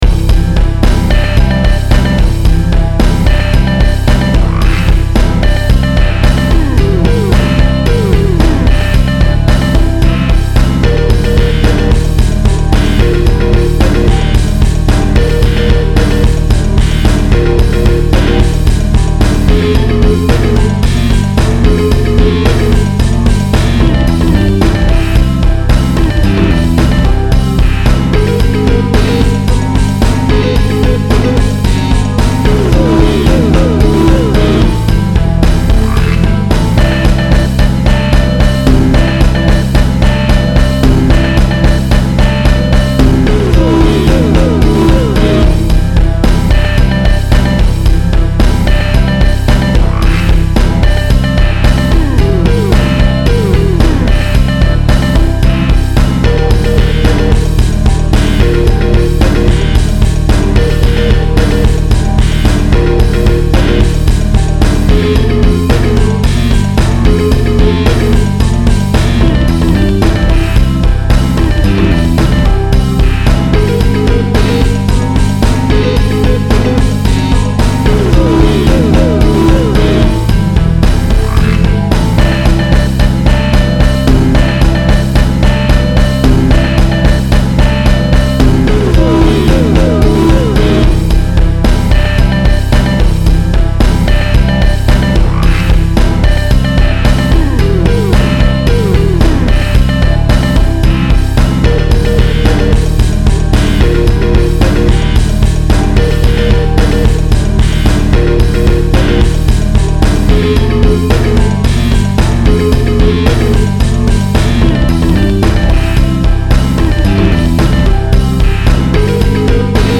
After listening to the basic rhythm section for a while, I decided to add a bit of Guiro in a few more measures, as well as to make it the Guiro louder, which in some respects serves as an aural cue that the singing for the verse or bridge starts at the next measure and is helpful when recording the singing, and I added a few more drums to the fourth beat accent that follows "A-N-A-R-C-H-Y" to make it deeper .
The notes played by the Baritone Electric Guitar have been modified to create more counterpoint or whatever, and the ending now does a Morse Code "counting" type of thing .